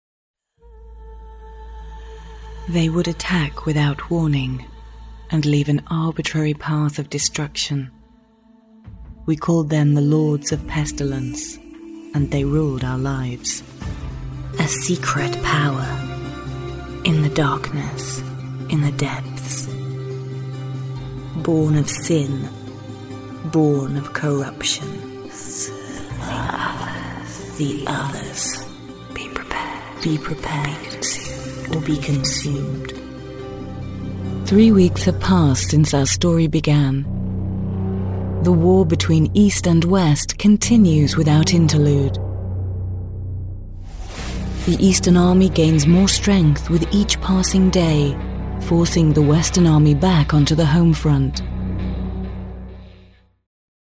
Videospiele
Meine Stimme wird als warm mit sanfter Würde beschrieben – ich liebe es, tiefgründige und modulierte Erzählungen vorzutragen – obwohl ich definitiv auch etwas mehr Abwechslung in lustige und spritzigere Lesungen bringen kann.
Neumann TLM103 Kondensatormikrofon
Heimstudio mit speziell gebauter schwebender Isolationskabine